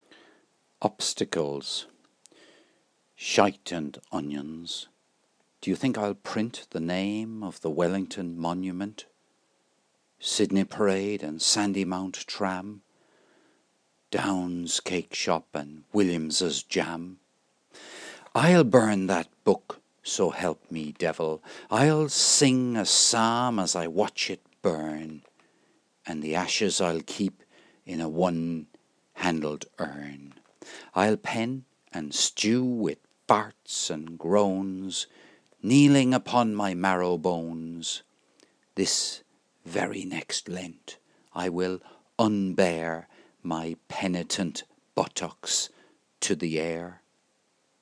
"Shite and onions" - poem by James Joyce